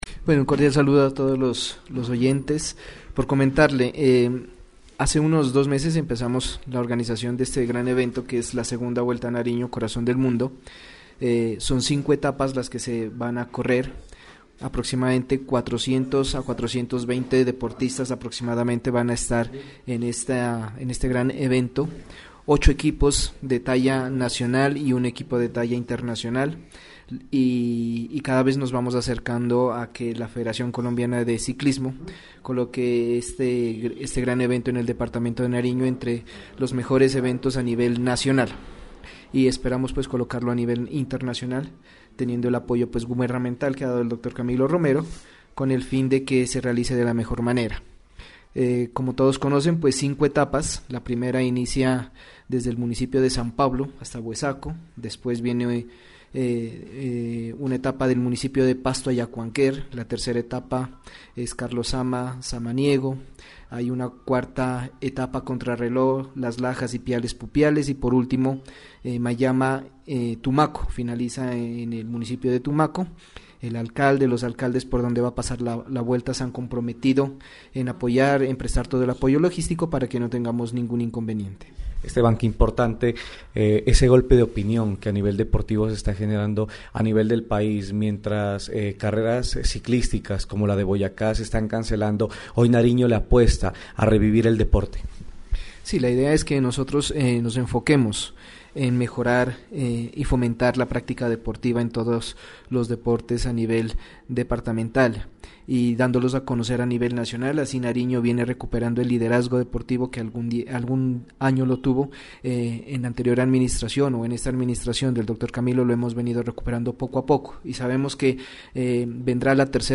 La segunda Vuelta a Nariño “Corazón del Mundo” en su versión 2017, fue presentada a los medios de comunicación en rueda de prensa cumplida en el auditorio de la Alcaldía Municipal de Pasto.